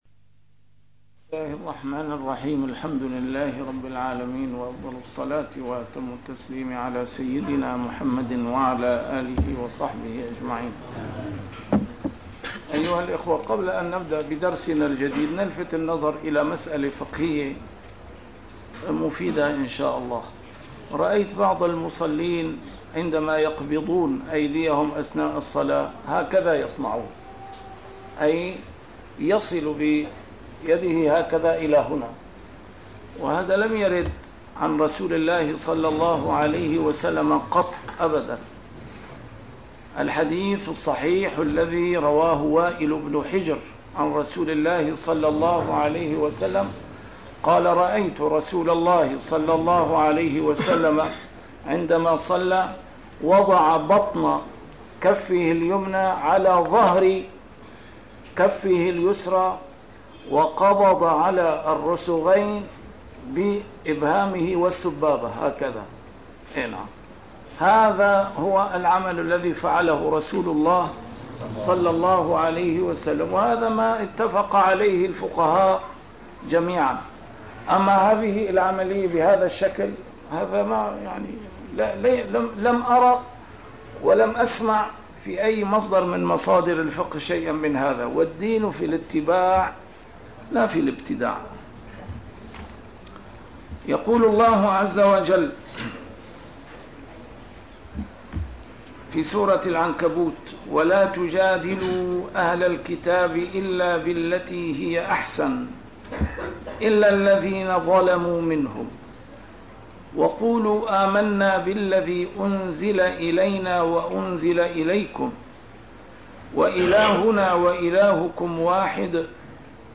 A MARTYR SCHOLAR: IMAM MUHAMMAD SAEED RAMADAN AL-BOUTI - الدروس العلمية - تفسير القرآن الكريم - تسجيل قديم - الدرس 305: العنكبوت 46-47